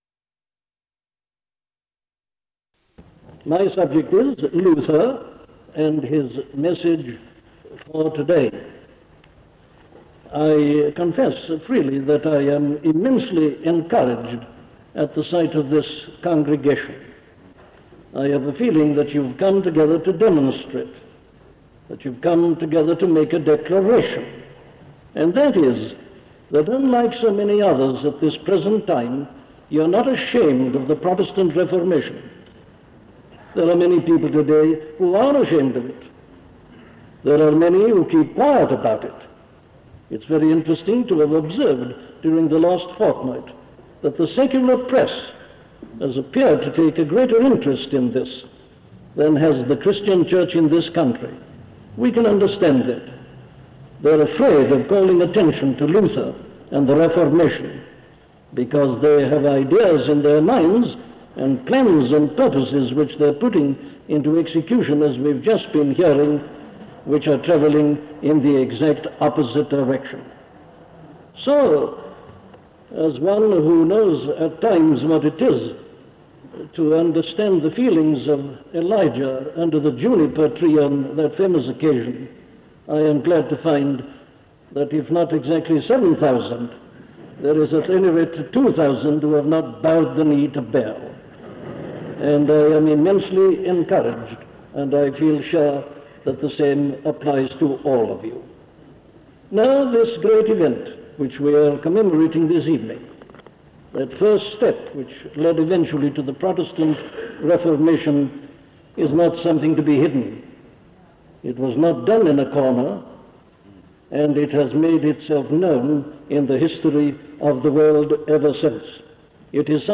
Luther and the Protestant Reformation - a sermon from Dr. Martyn Lloyd Jones